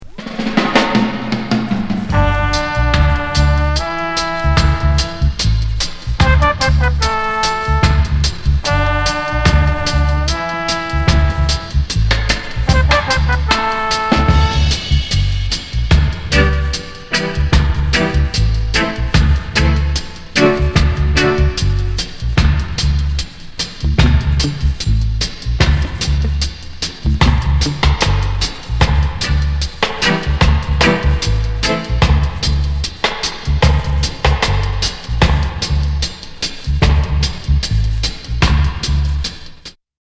B面はイメージがらっと変わって迫力ホ−ンに、 ロッカーズ・ビートの
ヘビー・ダブ！イカス！！